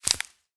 Minecraft Version Minecraft Version 1.21.5 Latest Release | Latest Snapshot 1.21.5 / assets / minecraft / sounds / block / cactus_flower / break5.ogg Compare With Compare With Latest Release | Latest Snapshot
break5.ogg